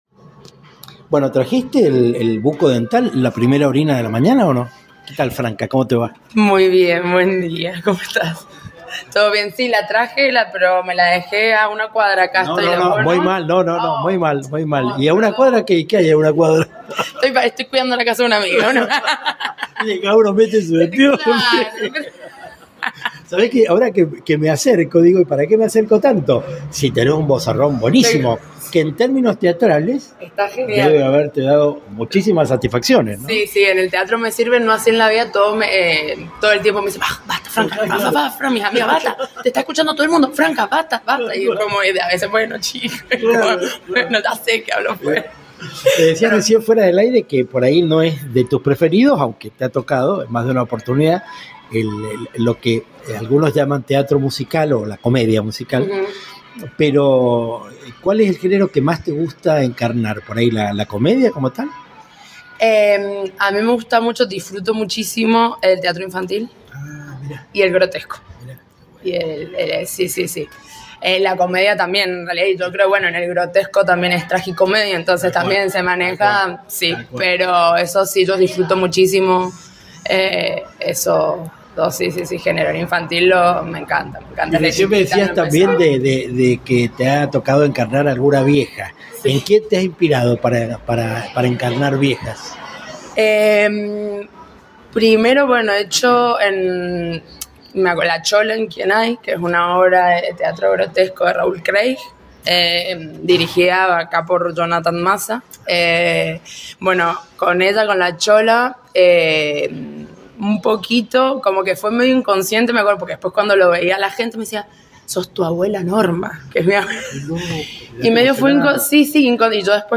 Entrevistas Latinocracia
Los temas se sucedieron en el contexto de la mesa del bar y al borde de un par de pocillos con café.